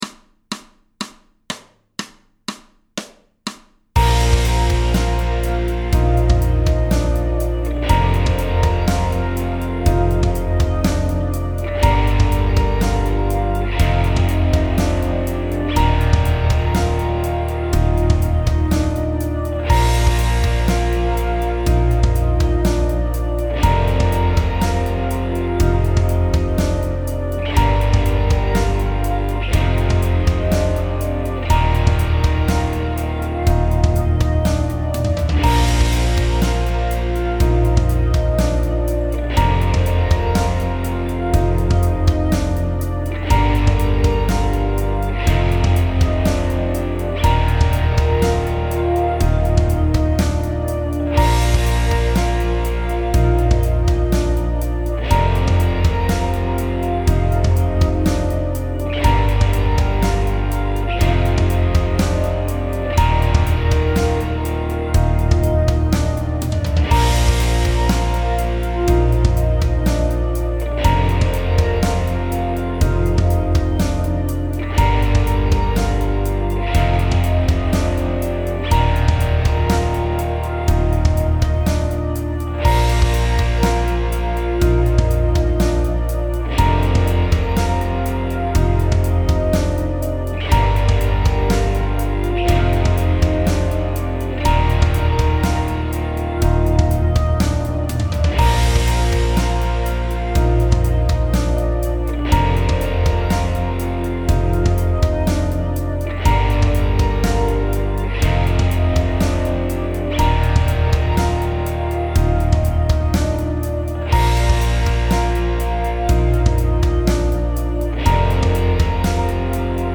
Jam Track PDF TAB Hi fam